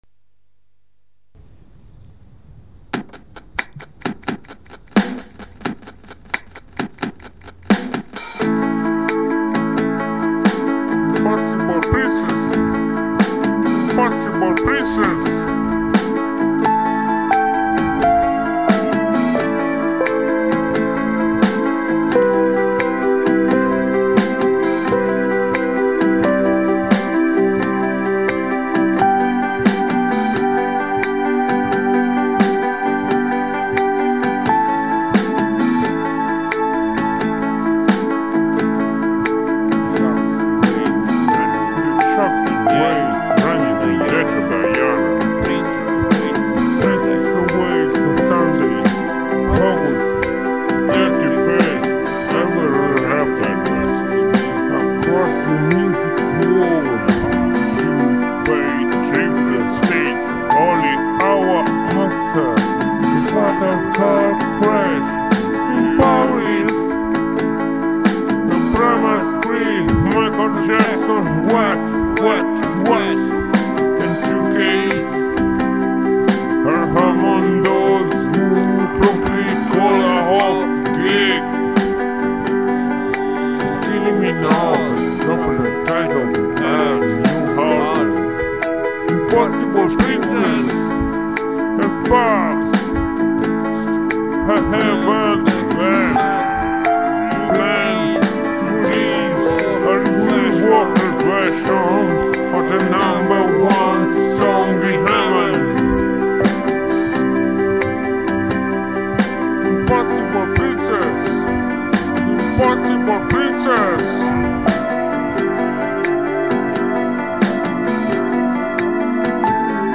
tastiere
voce.